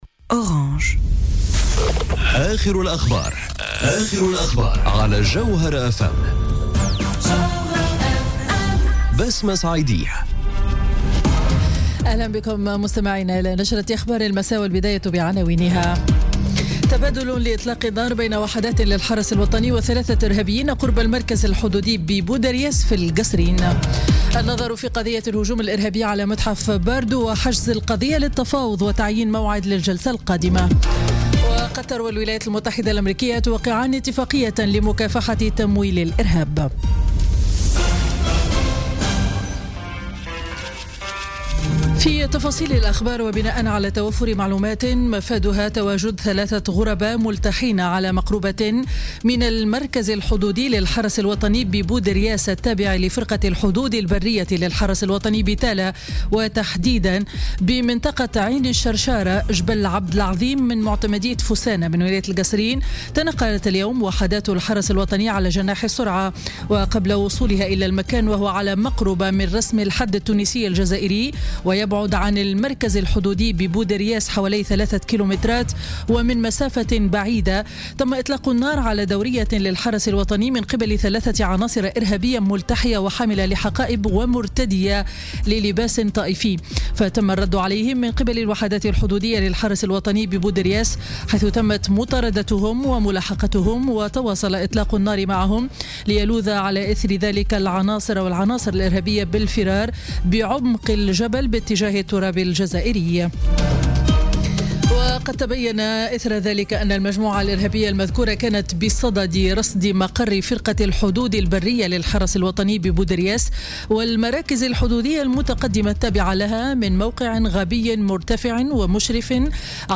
نشرة أخبار السابعة مساء ليوم الثلاثاء 11 جويلية 2017